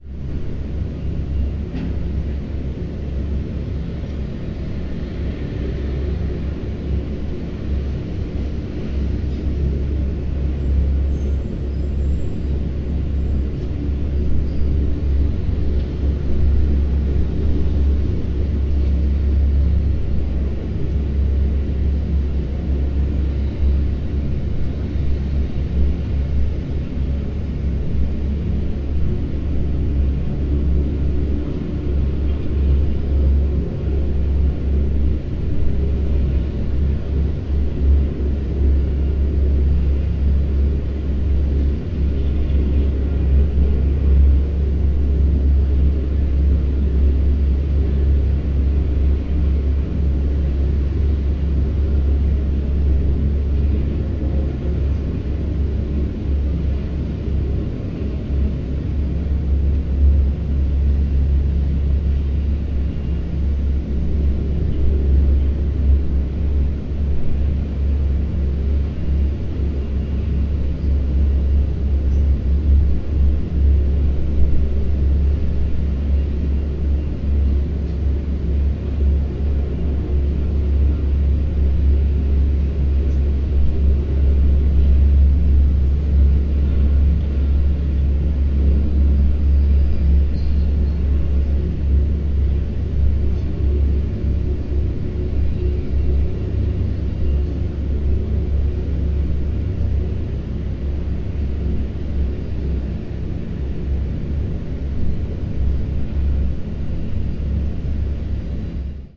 描述：在鹿特丹，在大约75米的高度，在中午时分，从一个长的现场录音的部分。我对这些部分进行了剪切和编辑：归一化、均衡化、包络化。索尼PCMD50
Tag: 城市 城市景观 现场录音 鹿特丹 街道 城市